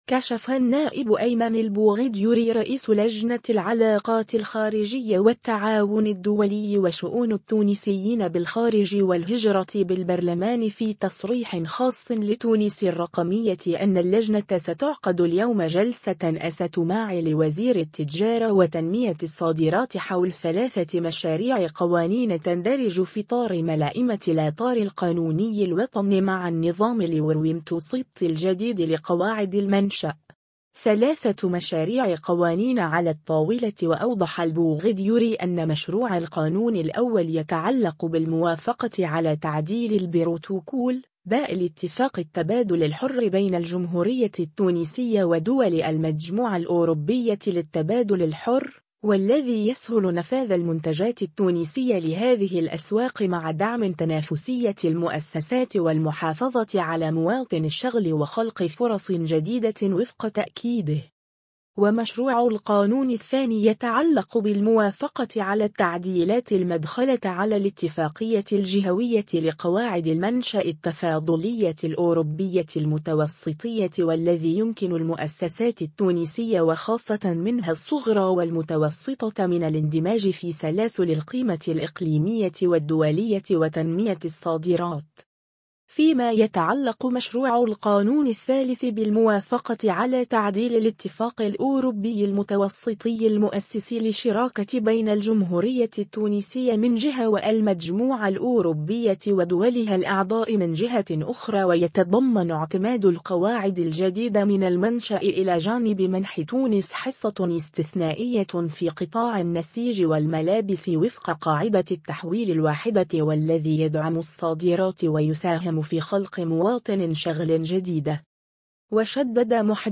كشف النائب أيمن البوغديري رئيس لجنة العلاقات الخارجية والتعاون الدولي وشؤون التونسيين بالخارج والهجرة بالبرلمان في تصريح خاص لـ”تونس الرقمية” أن اللجنة ستعقد اليوم جلسة إستماع لوزير التجارة وتنمية الصادرات حول 3 مشاريع قوانين تندرج في اطار ملائمة الاطار القانوني الوطني مع النظام الاورومتوسطي الجديد لقواعد المنشأ.